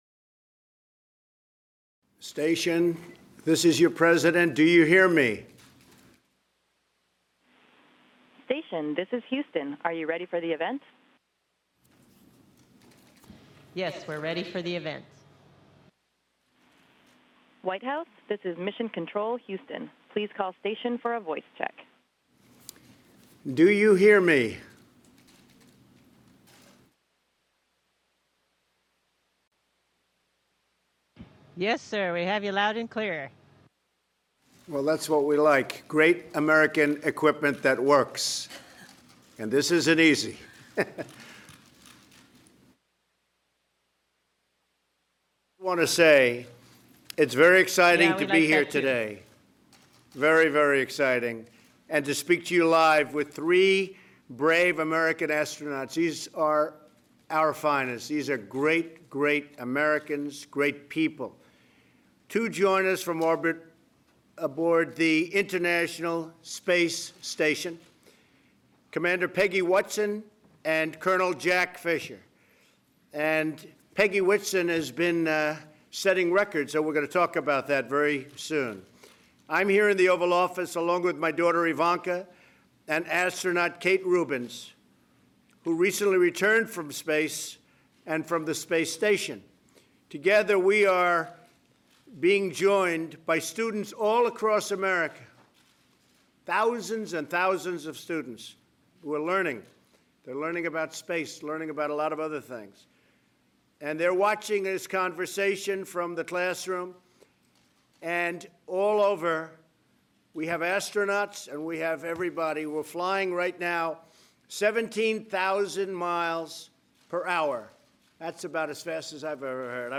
U.S. President Donlad Trump talks with astronauts who are aboard the International Space Station
Recorded 2017 April 24